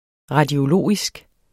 Udtale [ ʁɑdjoˈloˀisg ]